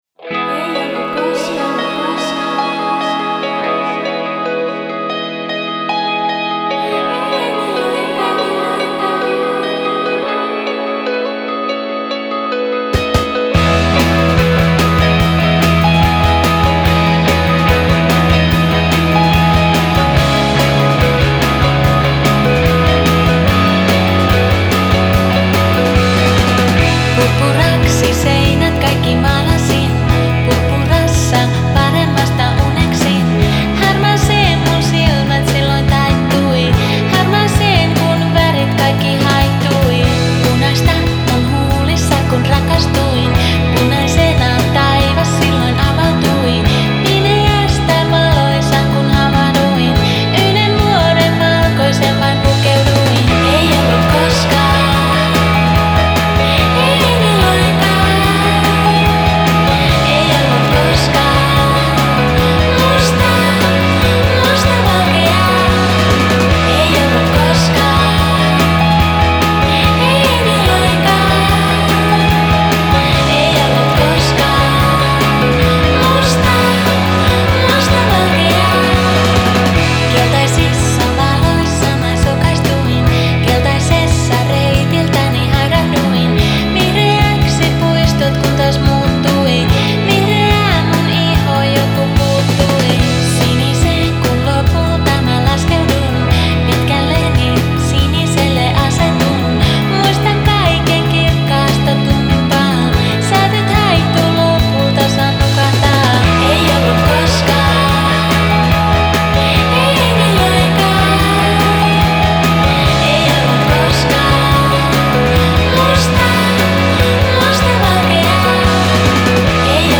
Style: Dream Pop